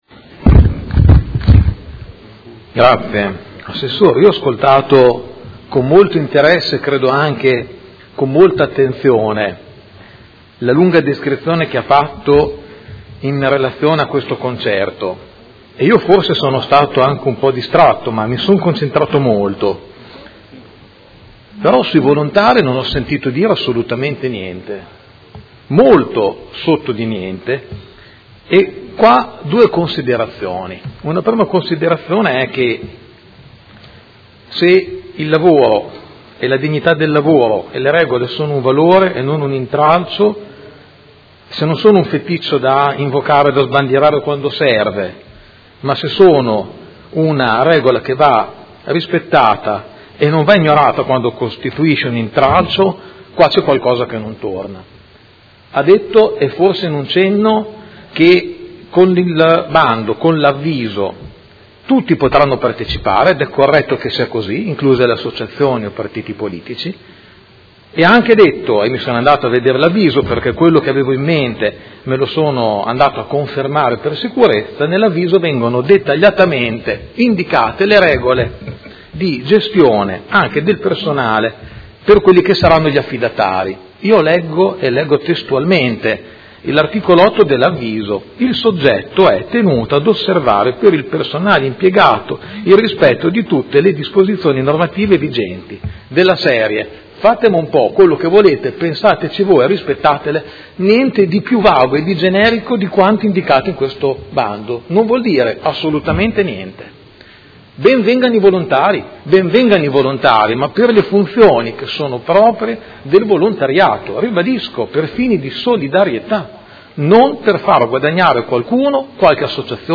Seduta del 30/03/2017. Dibattito inerente le interrogazioni sul concerto di Vasco Rossi